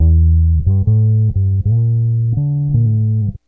BASS 37.wav